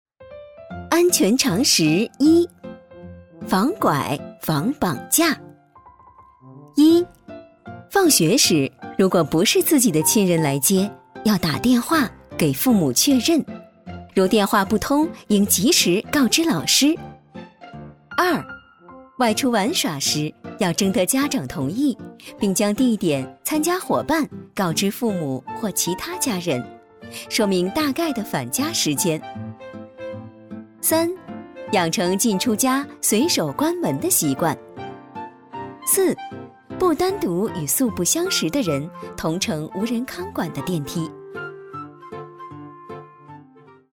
女国语330